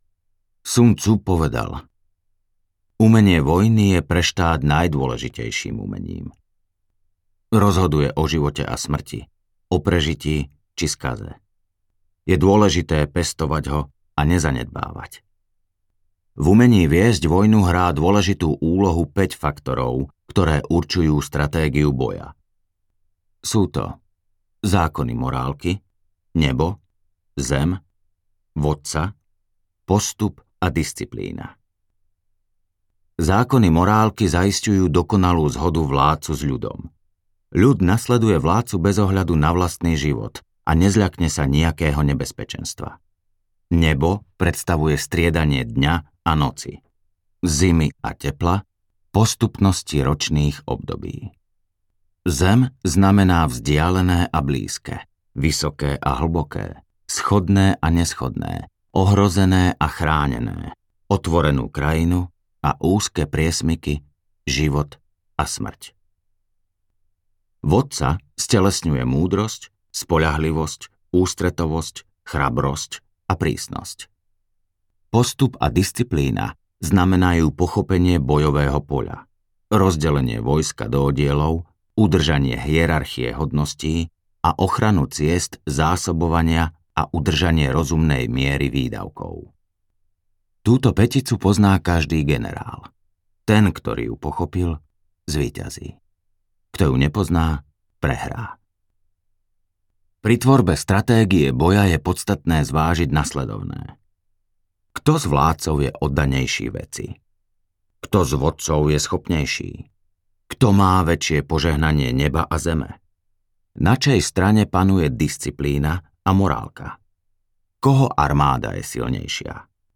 audiokniha
Nechajte sa hlasom rozprávača previesť múdrosťou Sun-c' s jasnosťou a naliehavosťou.